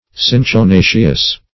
Search Result for " cinchonaceous" : The Collaborative International Dictionary of English v.0.48: Cinchonaceous \Cin`cho*na"ceous\, a. Allied or pertaining to cinchona, or to the plants that produce it.
cinchonaceous.mp3